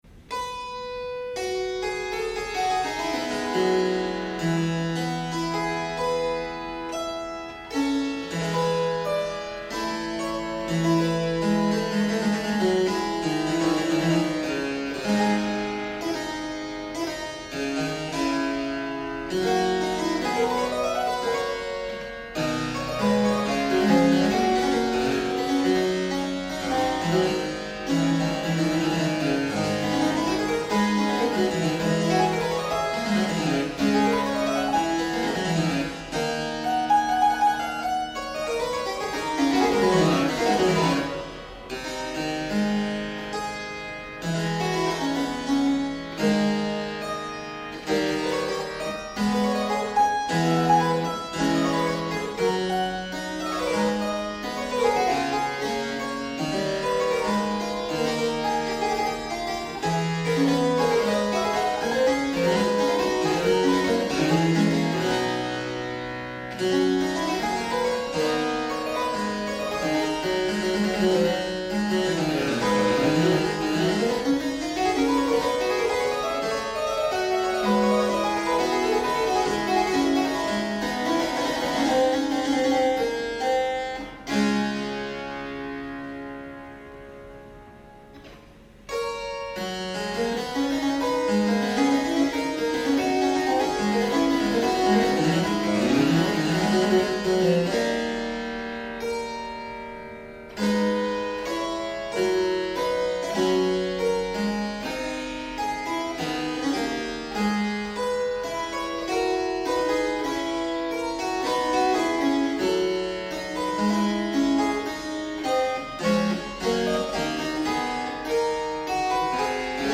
In the meantime, below are samples of live recordings.